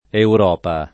Europa [ eur 0 pa ]